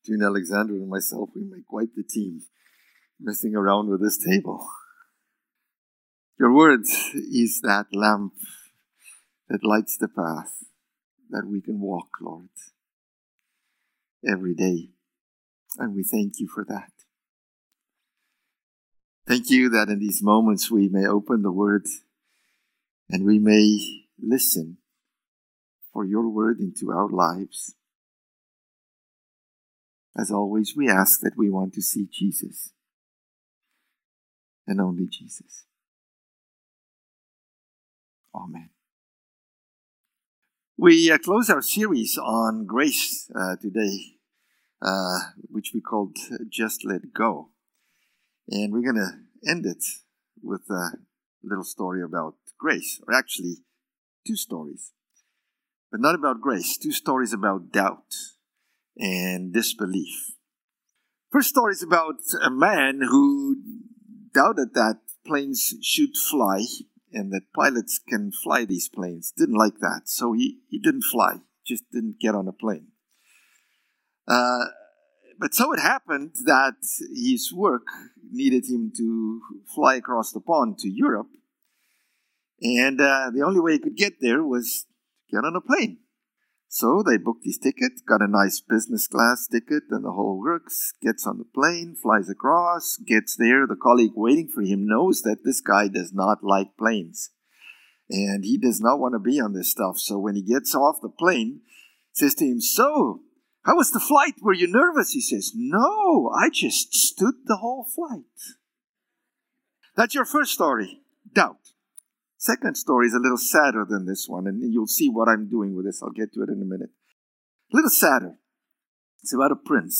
February-1-Sermon.mp3